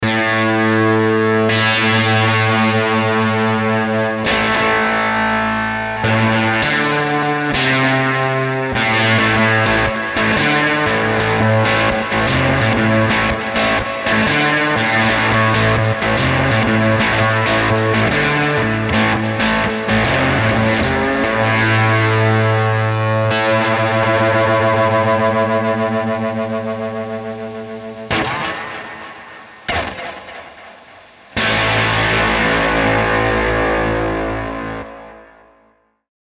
描述：播放Fender Stratocaster
标签： 100 bpm Rock Loops Guitar Electric Loops 3.13 MB wav Key : Unknown
声道立体声